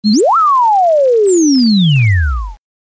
جلوه های صوتی
برچسب: دانلود آهنگ های افکت صوتی اشیاء دانلود آلبوم صدای کلیک موس از افکت صوتی اشیاء